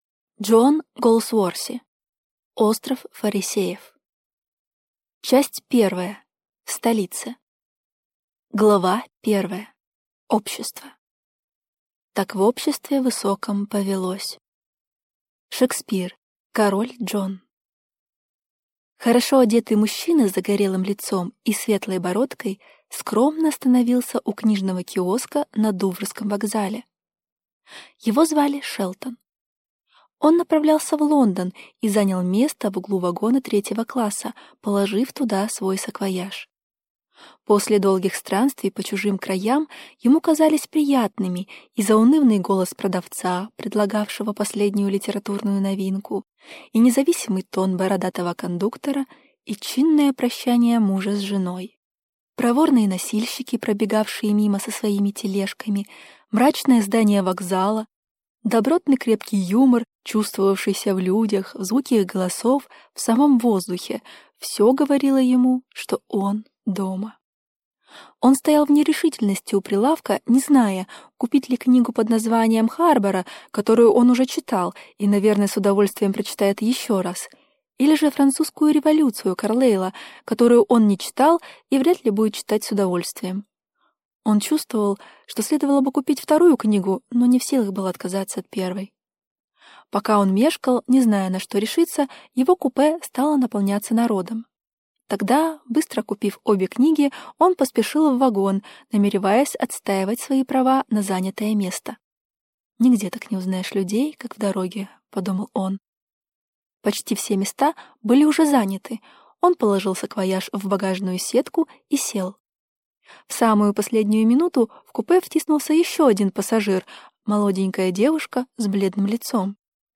Аудиокнига Остров фарисеев | Библиотека аудиокниг